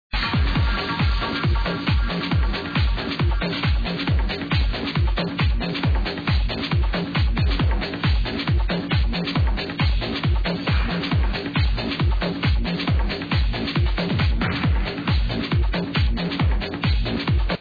slightly amped up version